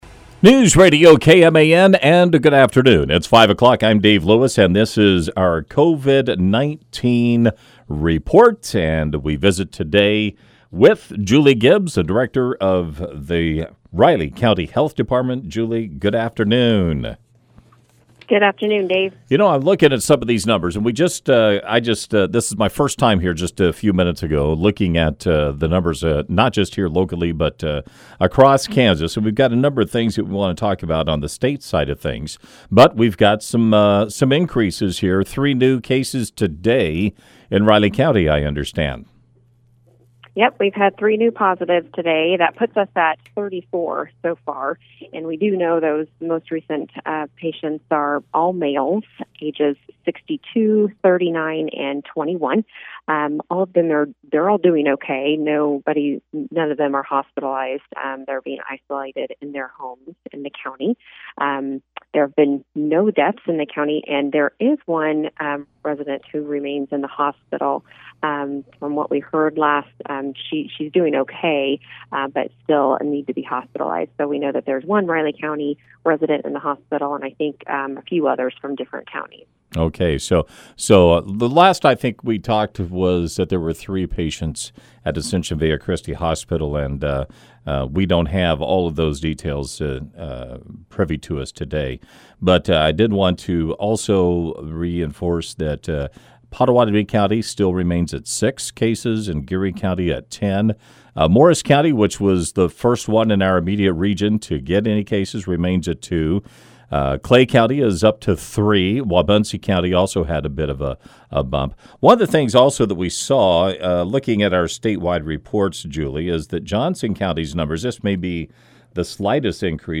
Julie Gibbs, Director of the Riley County Health Department, joined KMAN in the 5 PM hour to give our daily COVID-19 update.